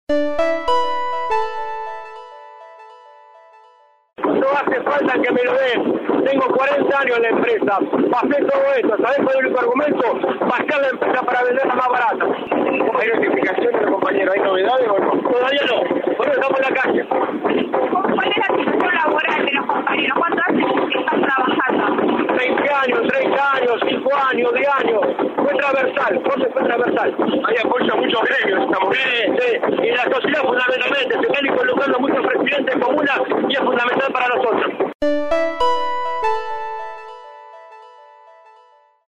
afirmó por el móvil de LT3 que es el “único motivo” que justifica los despidos